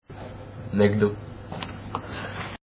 前に“トリビアの泉”でやってた気がするけど、フランス人は本当に
McDonaldと発音する。